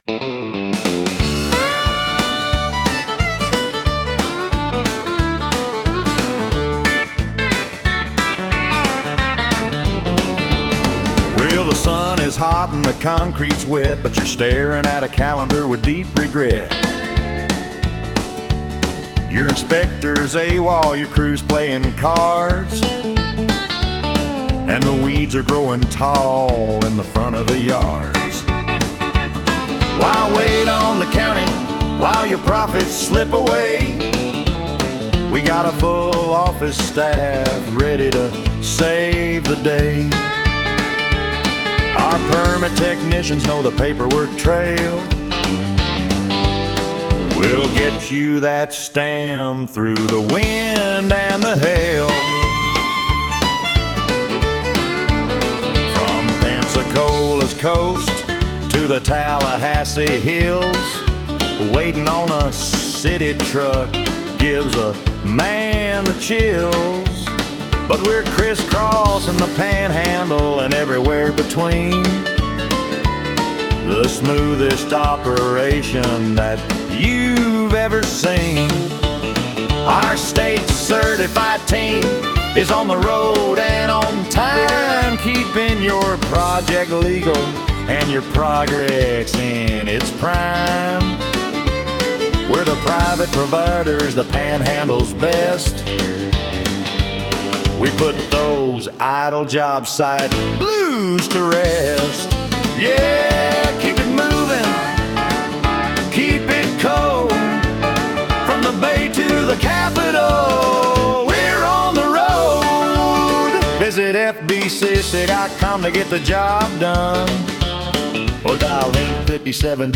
FBCCA-On-The-Job-Panhandle-Honkytonk-Mix.mp3